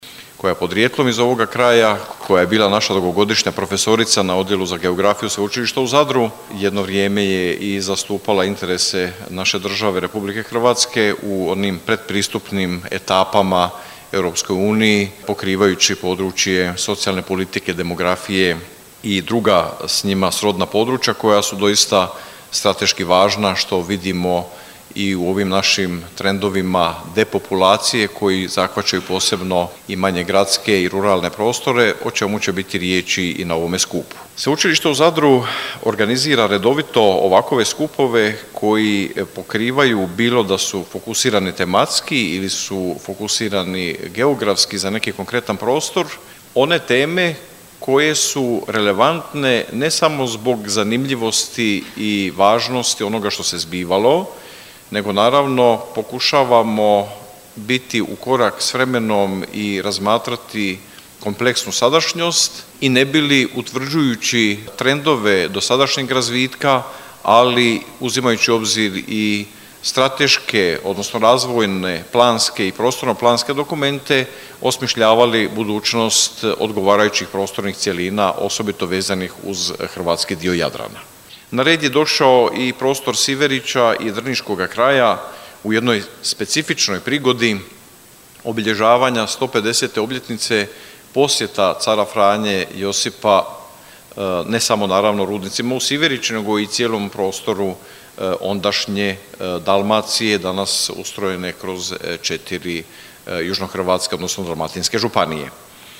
Znanstveni skup o Siveriću – pogled u povijest s ciljem jasnije budućnosti